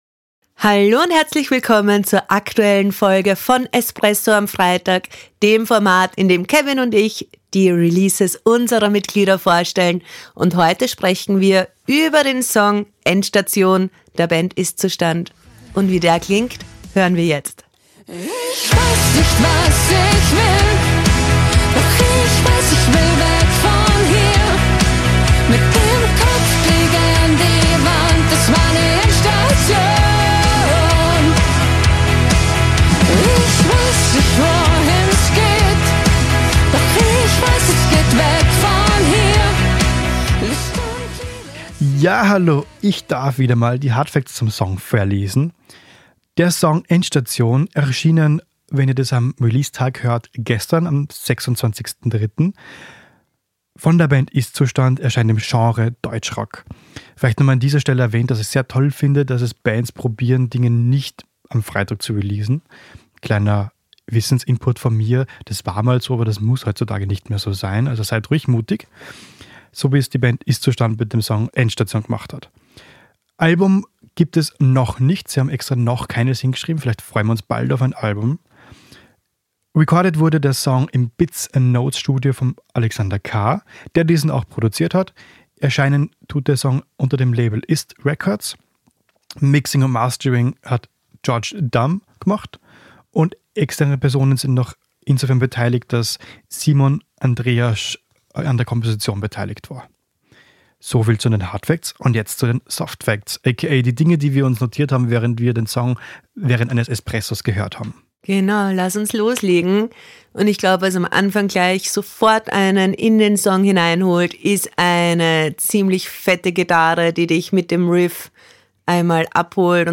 Die Release-Rezension für Newcomer